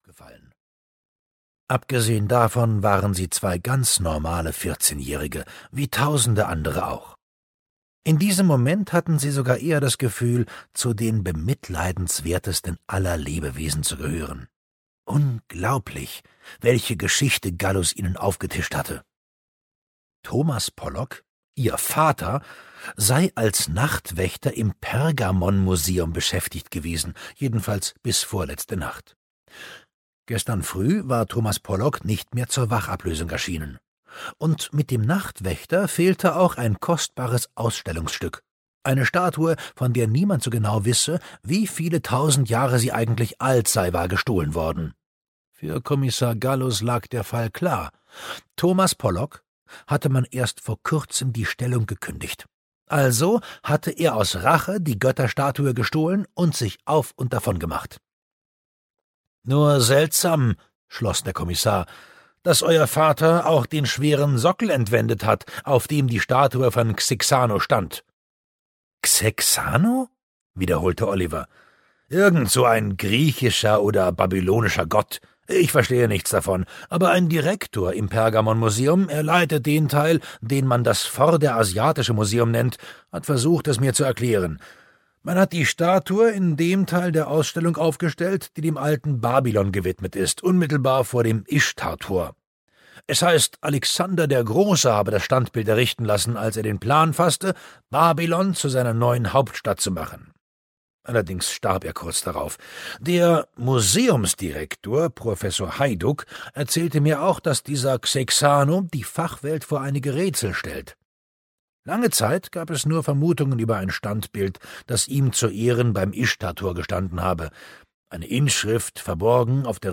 Das Museum der gestohlenen Erinnerungen - Ralf Isau - Hörbuch